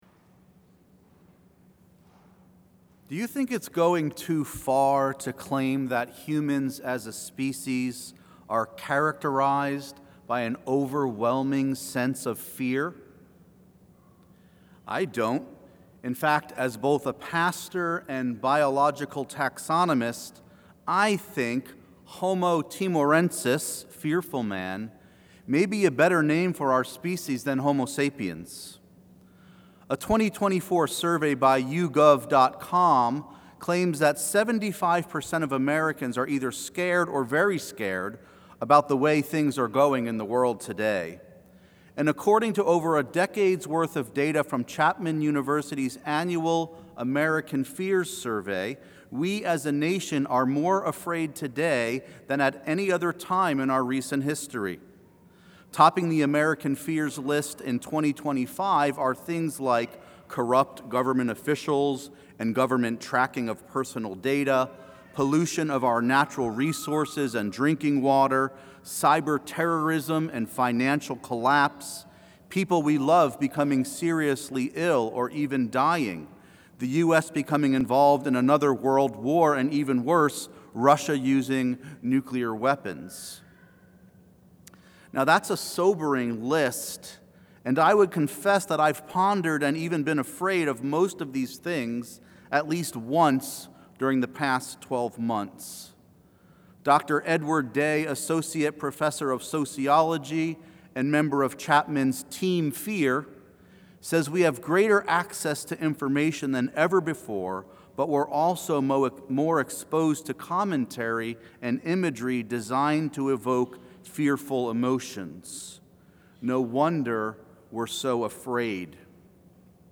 Download Download From this series Current Sermon Don't Be Afraid
on the 4th Sunday of Advent